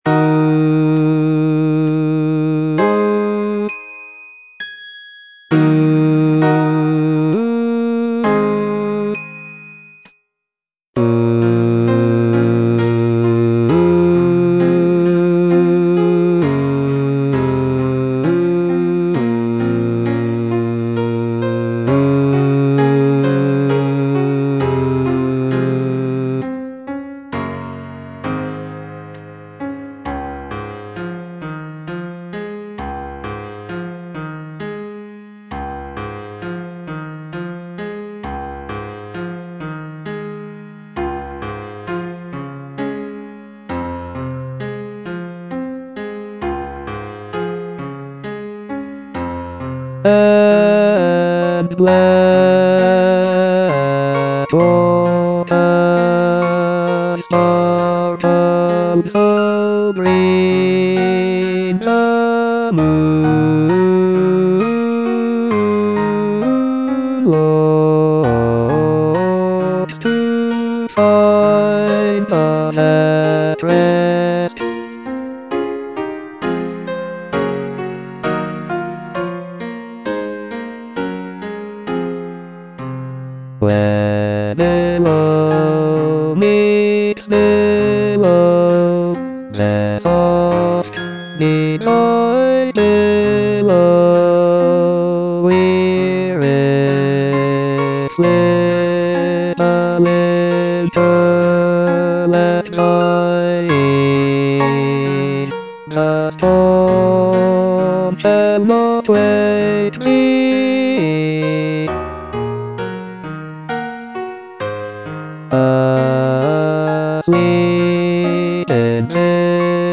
Bass I (Voice with metronome)